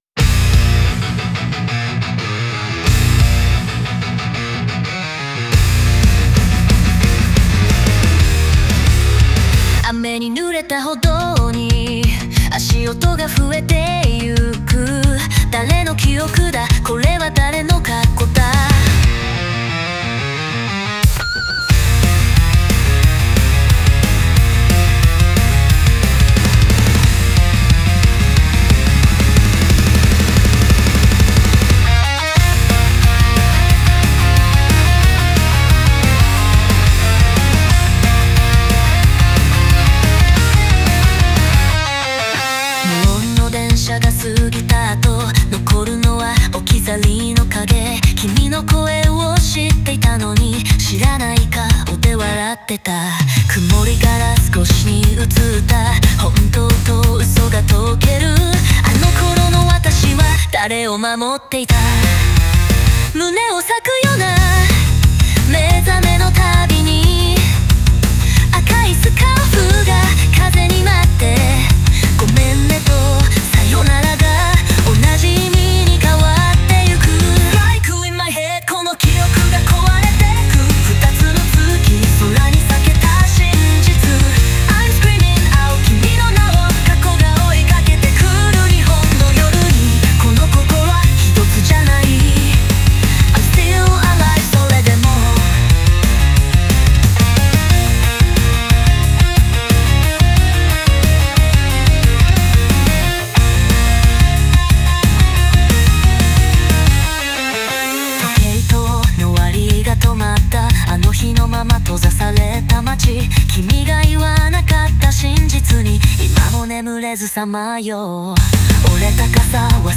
オリジナル曲♪
この歌詞は、記憶と過去に翻弄される主人公の内面を、日本の風景を背景に描いたロックバラードです。
歪んだギターと重たいリズムが、逃れられない過去や錯綜する感情を象徴。